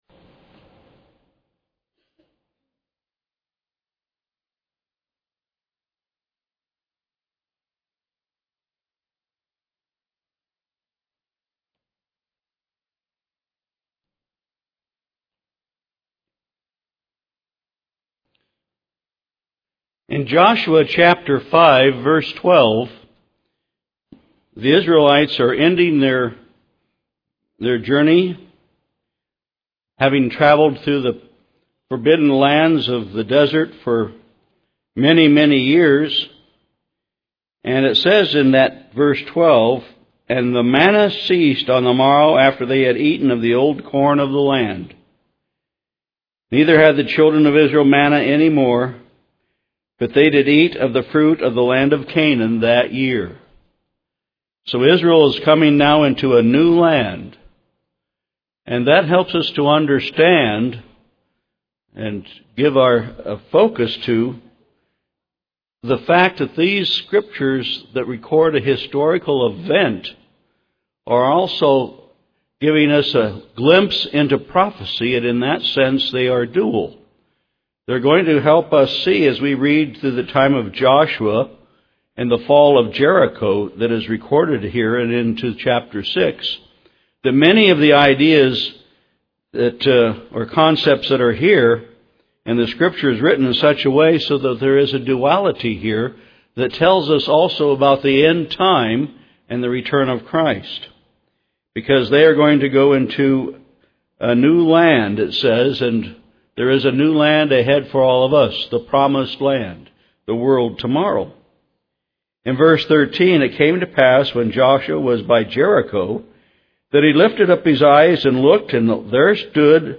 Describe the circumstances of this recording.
Given in Ft. Lauderdale, FL